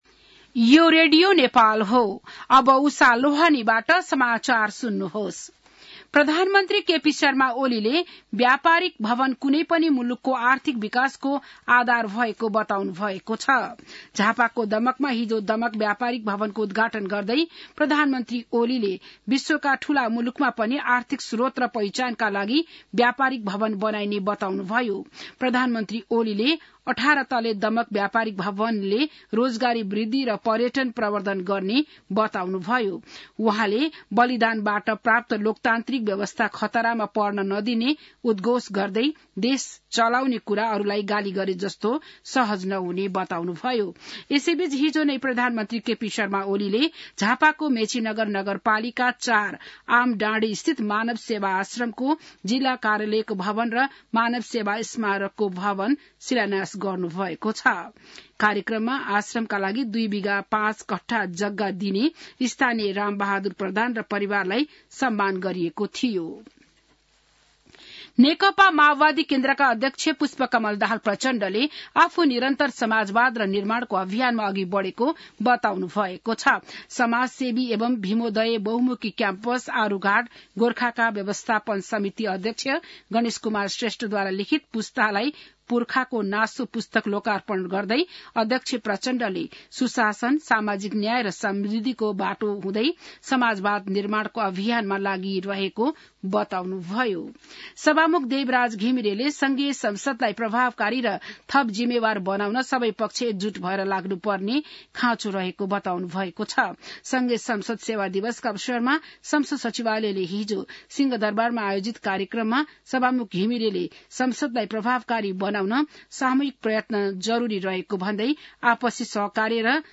बिहान १० बजेको नेपाली समाचार : २५ मंसिर , २०८१